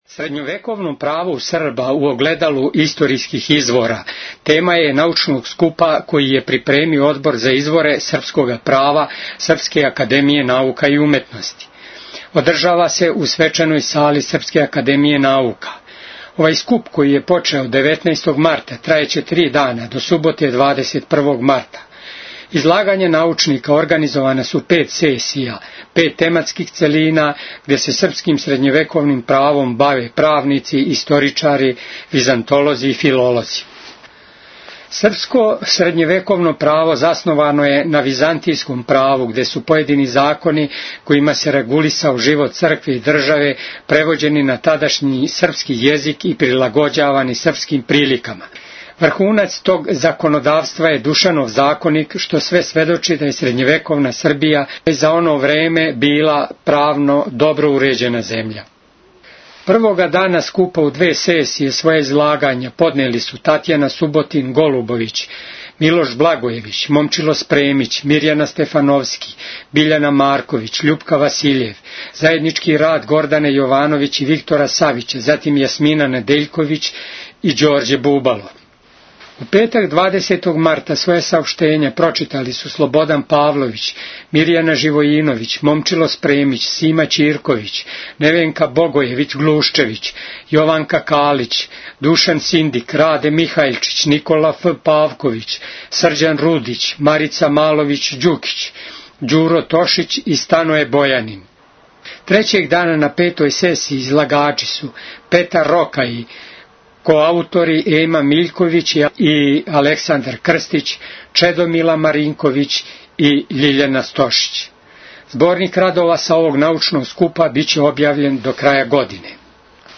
Извјештаји (2091)
Одржава се у свечаној сали Српске академије наука.